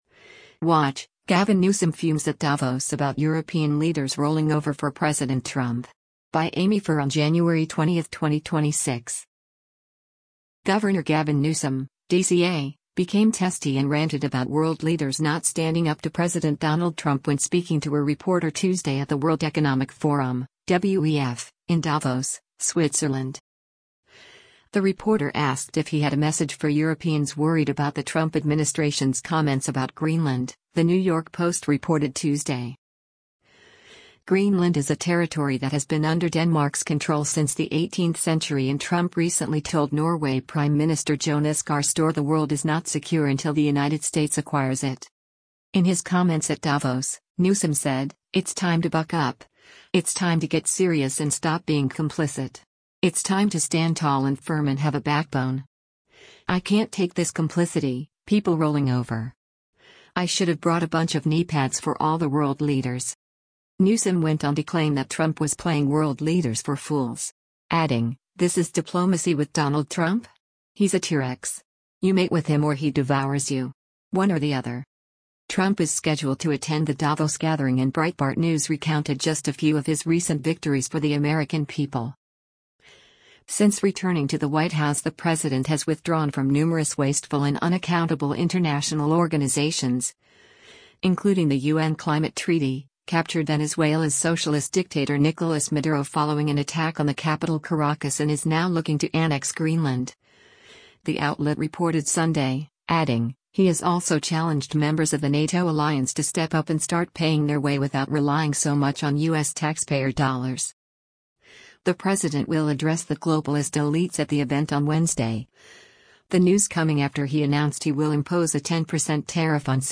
Gov. Gavin Newsom (D-CA) became testy and ranted about world leaders not standing up to President Donald Trump when speaking to a reporter Tuesday at the World Economic Forum (WEF) in Davos, Switzerland.